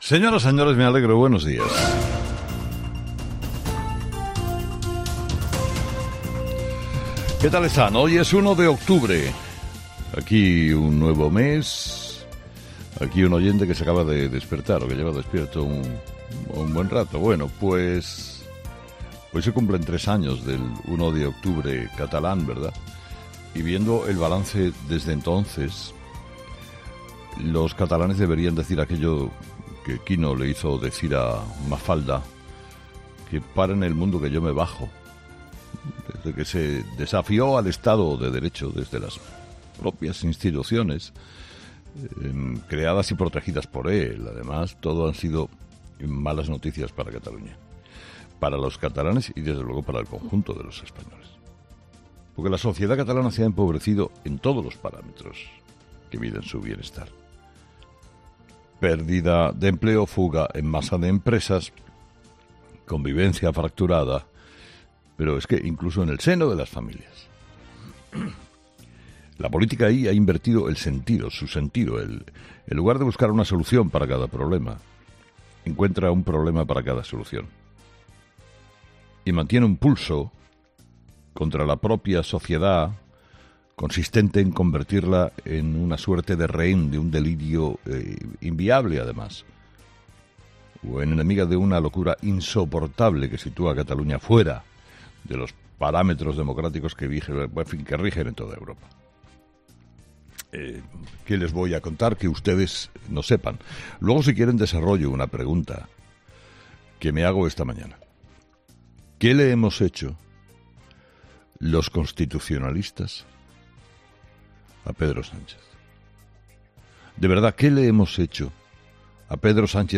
Carlos Herrera ha comenzado este jueves su primera columna en 'Herrera en COPE' recordando el aniversario del referéndum ilegal que se llevó a cabo en Cataluña en el año 2017 y haciendo balance de cuál es la situación actual en la comunidad.